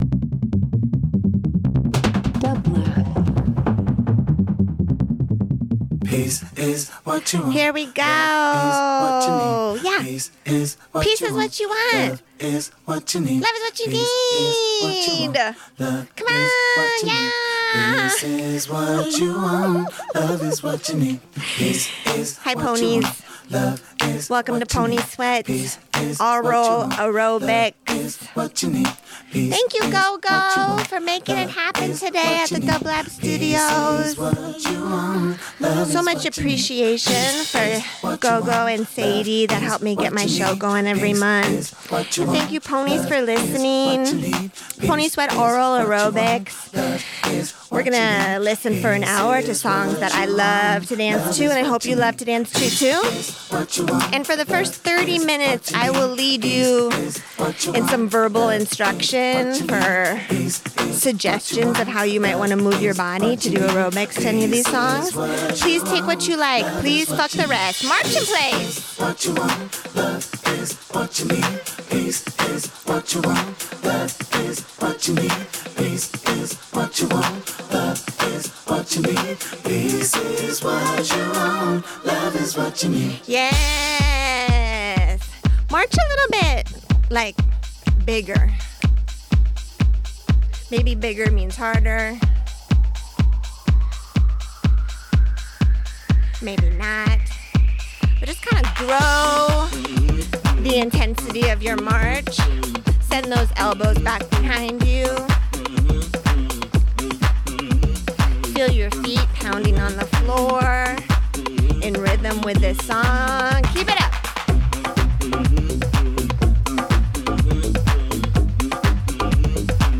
Avant-Garde Dance New Wave R&B